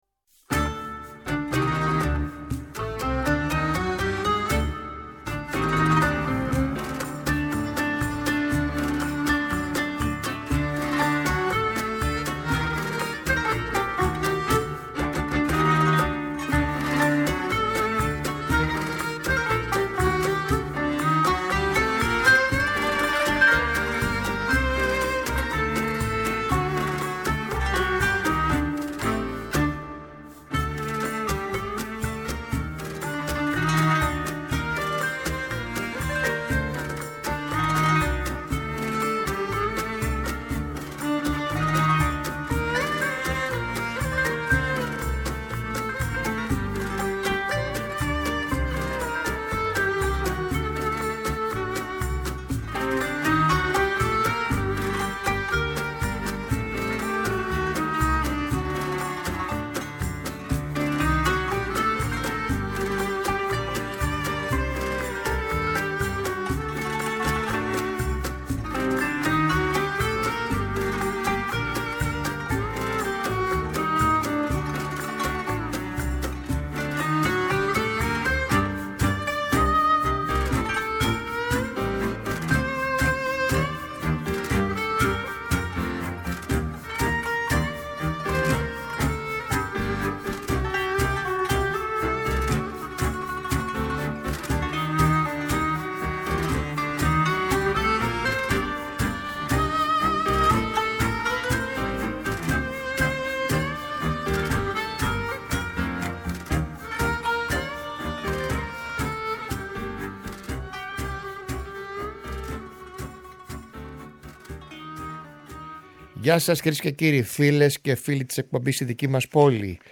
Στην εκπομπή ακούστηκαν μουσικές κυρίως από την ιδιαίτερη πατρίδα της.